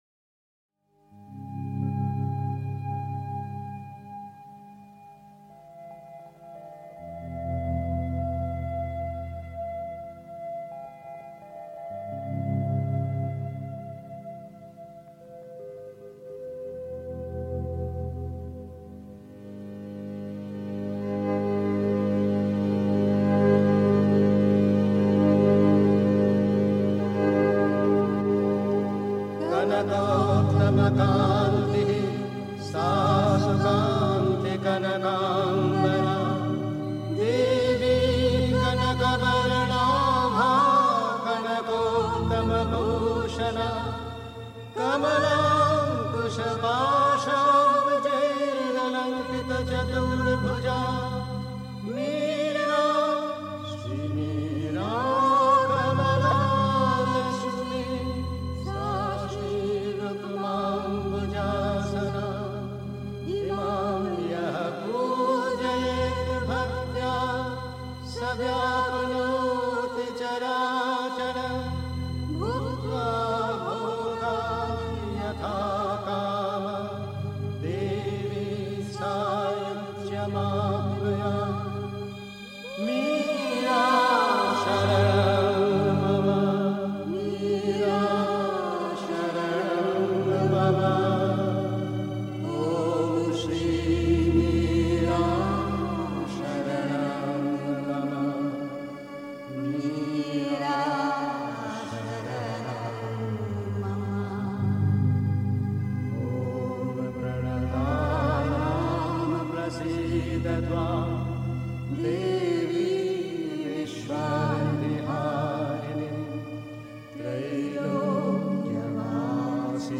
Pondicherry. 2. Wenn alles verloren scheint, kann alles gerettet werden (Die Mutter, CWM Vol 14, p. 90) 3. Zwölf Minuten Stille.